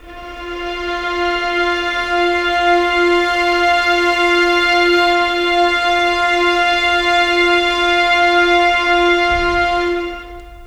Rock-Pop 22 Viola _ Violins 01.wav